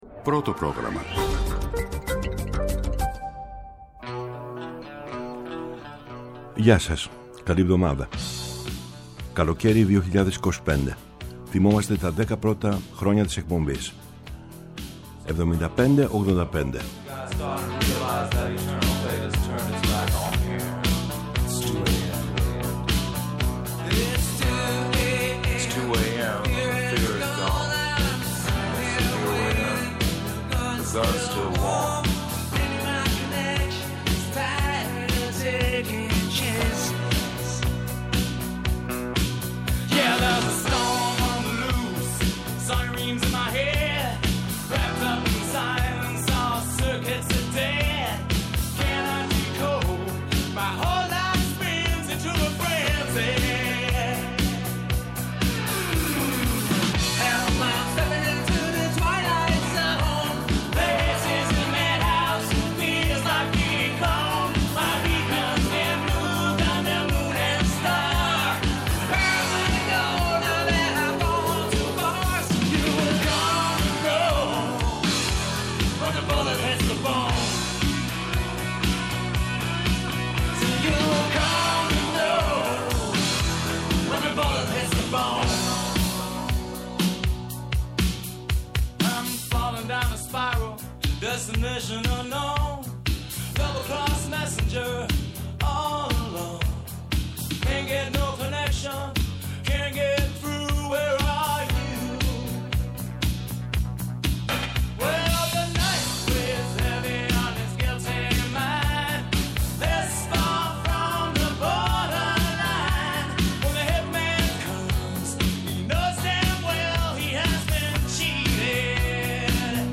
rock / disco